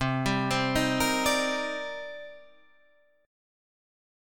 C Minor 9th